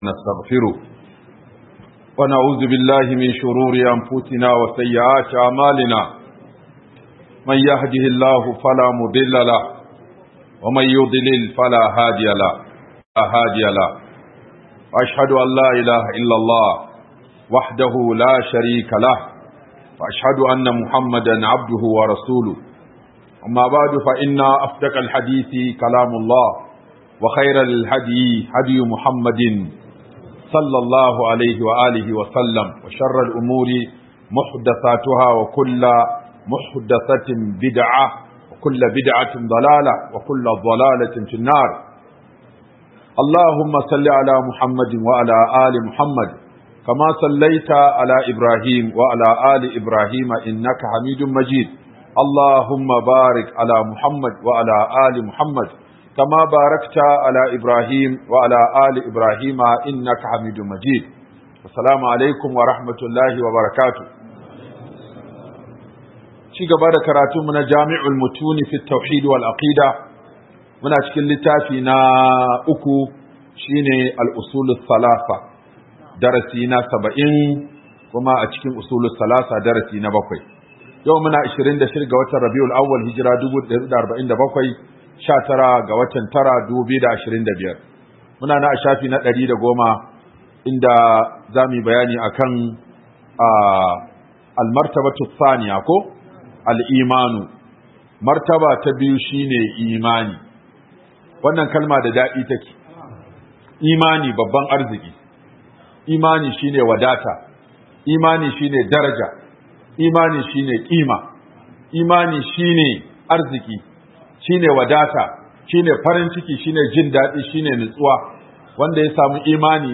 Alamomin Tashin Qiyama - Huduba by Sheikh Aminu Ibrahim Daurawa